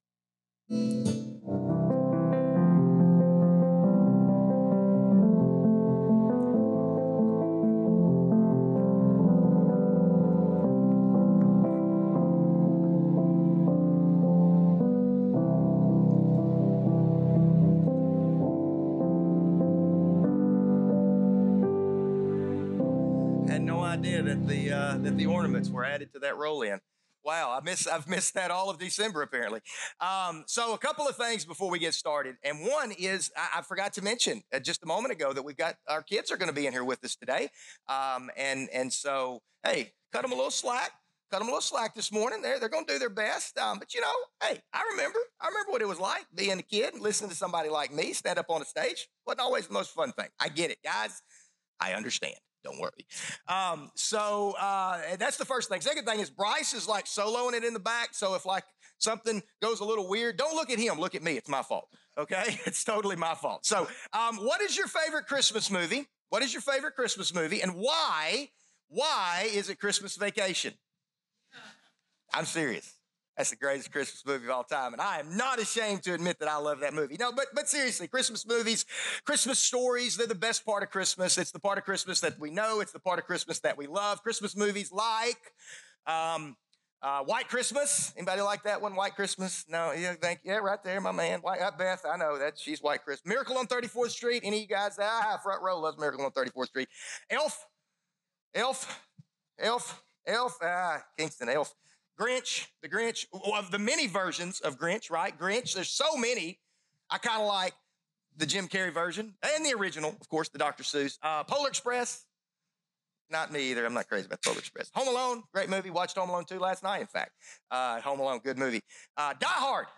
2024 Current Sermon 8 of 9|Catching Christmas Catching Christmas Right On Time...